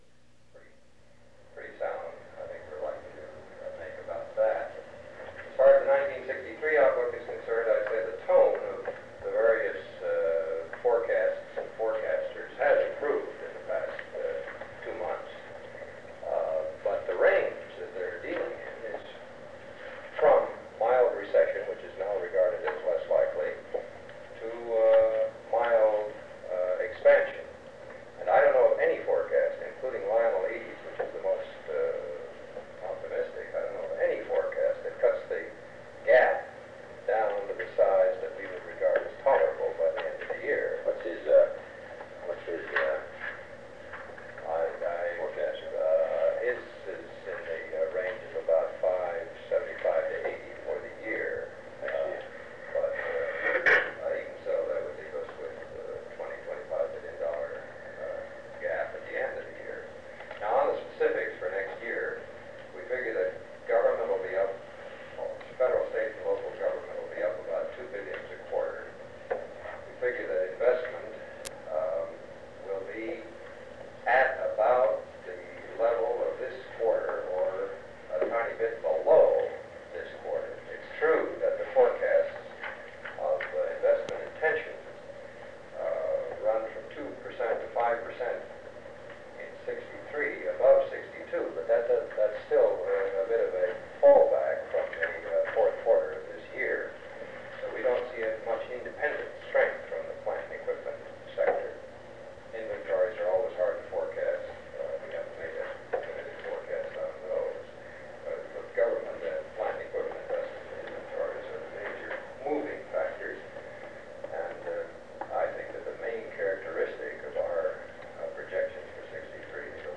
Secret White House Tapes | John F. Kennedy Presidency Meeting on the Economy Rewind 10 seconds Play/Pause Fast-forward 10 seconds 0:00 Download audio Previous Meetings: Tape 121/A57.